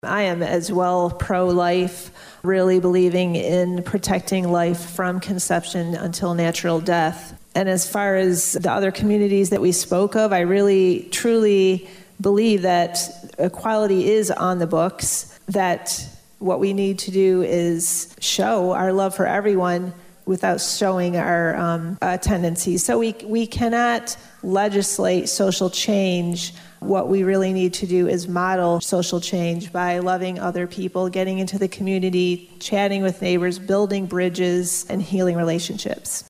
Adrian, MI – With the reversal of Roe v. Wade last week, the topic of abortion will now go to the states to decide…and a few local candidates for State House were asked about reproductive rights at a recent forum.